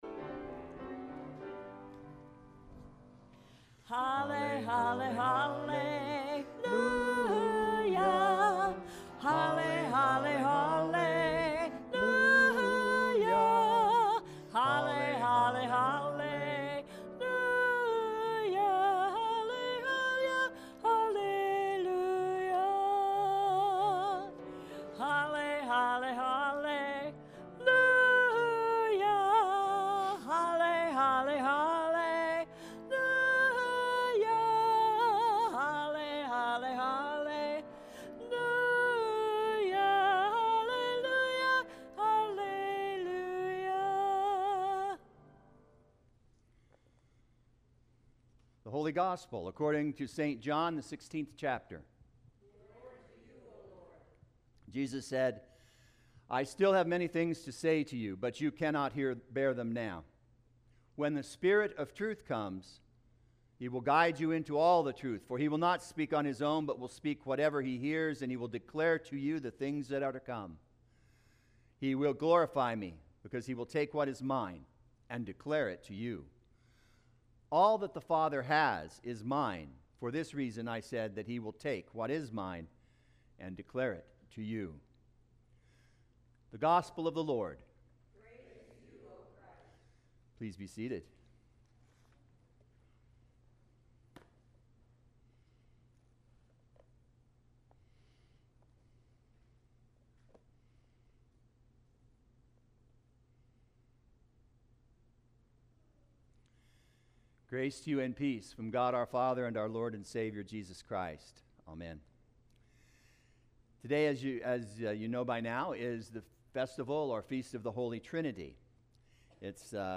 Sermon 06.15.25